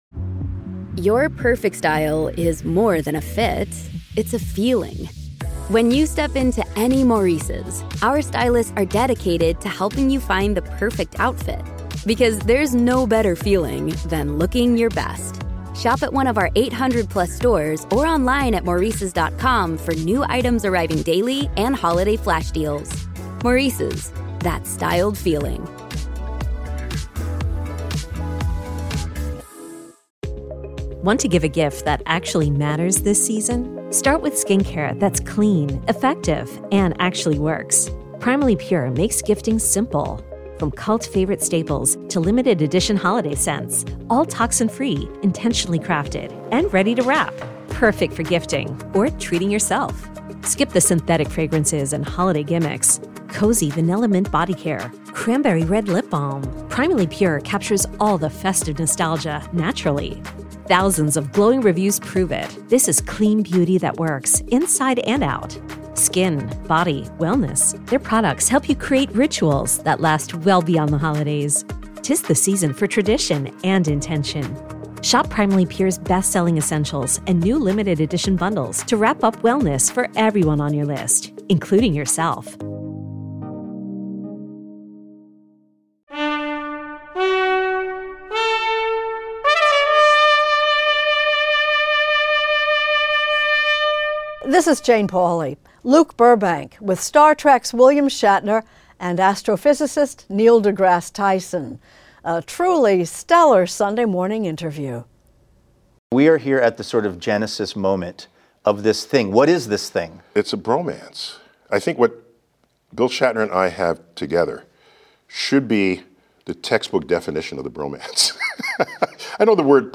Extended Interview: William Shatner & Neil deGrasse Tyson
"Star Trek" legend William Shatner and Hayden Planetarium director Neil deGrasse Tyson talk with Luke Burbank about teaming up for a stage show, "The Universe Is Absurd!" in which they discuss the wonders of science.